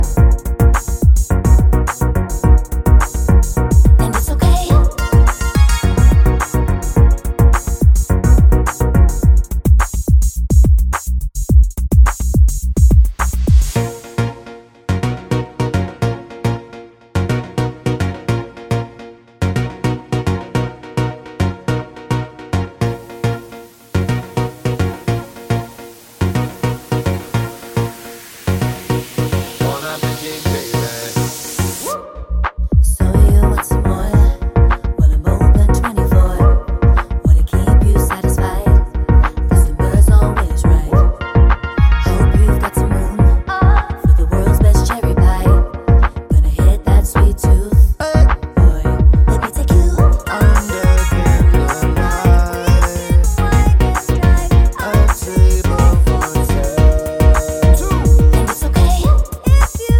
for solo female Pop (2010s) 3:47 Buy £1.50